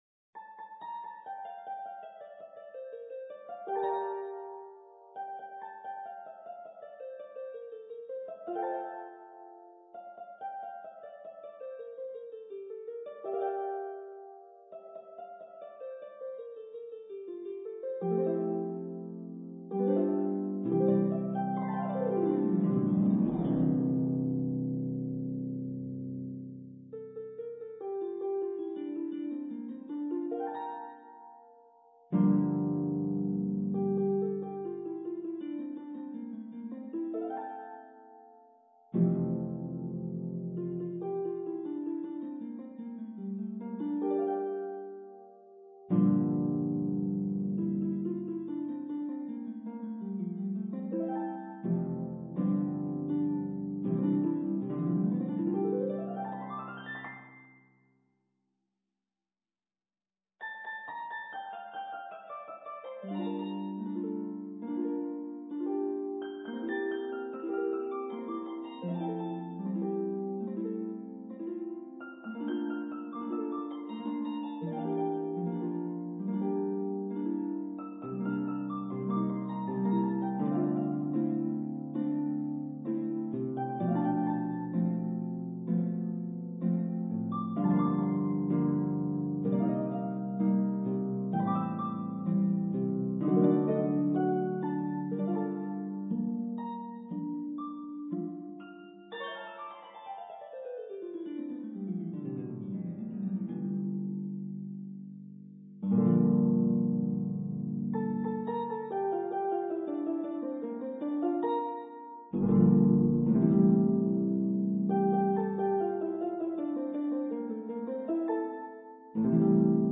for harp
Coolly neo-classical with moments of heightened emotion.